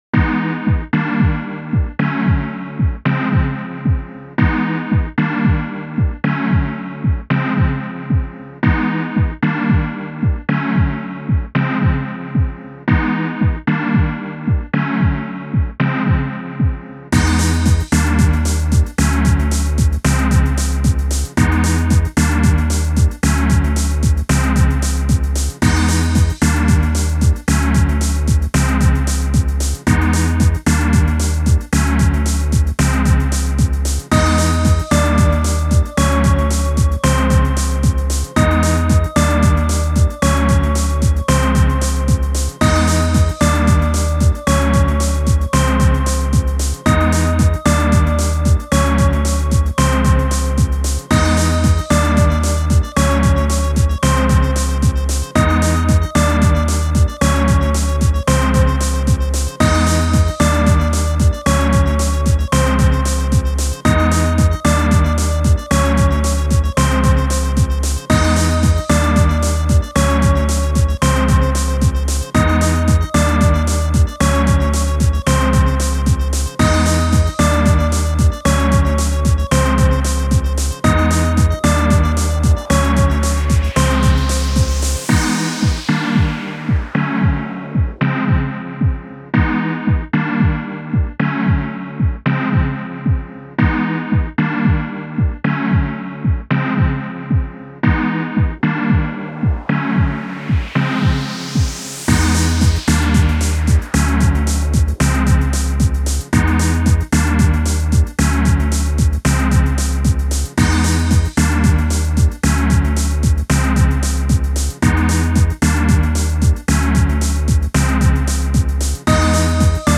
楽器のレパートリー的には工場系のBGMにもきこえるかも。
調性の小さい浮遊感を持つ独特なコード進行で不思議な世界へLet’s Go!するでー。